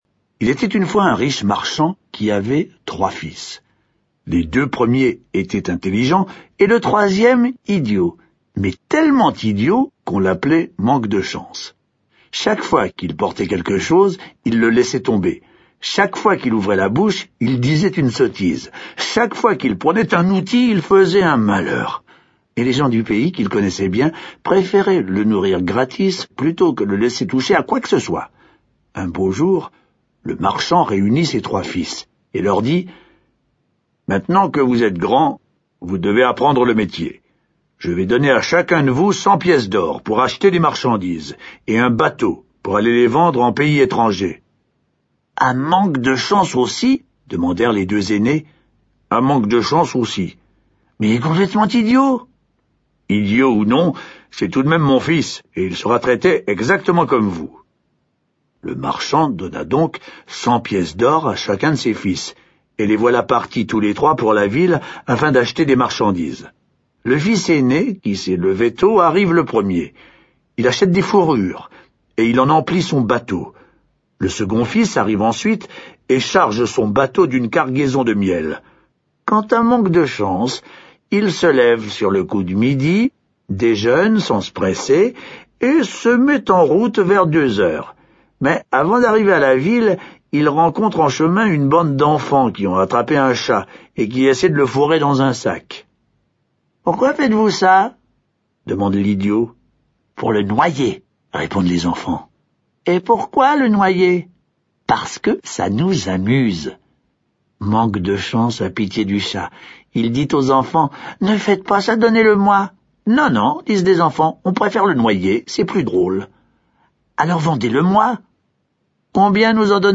Genre : Livre Audio.